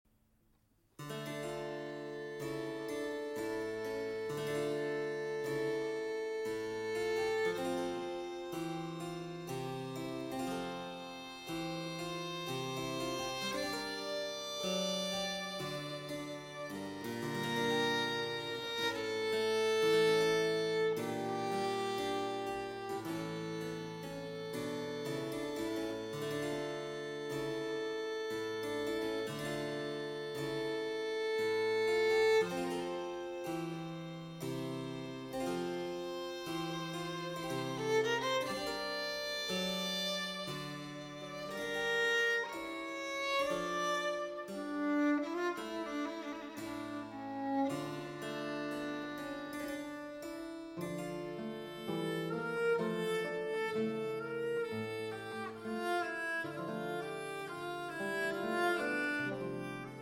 Baroque Ensemble